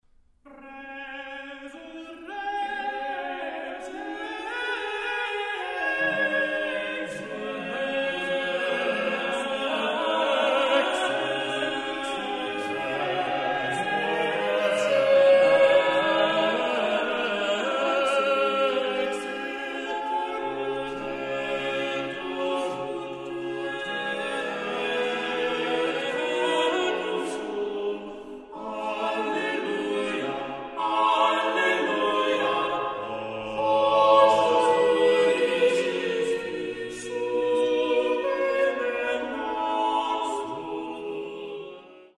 a darkly penitential motet